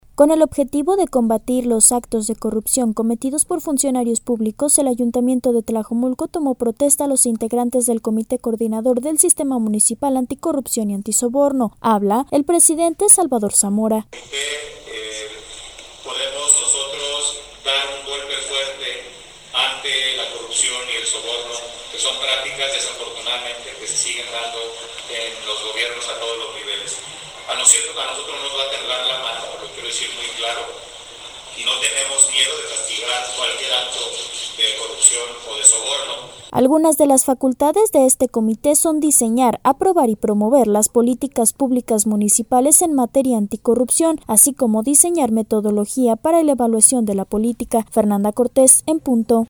Con el objetivo de combatir los actos de corrupción cometidos por funcionarios públicos, el ayuntamiento de Tlajomulco tomó protesta a los integrantes del Comité Coordinador del Sistema Municipal Anticorrupción y Antisoborno. Habla el presidente Salvador Zamora: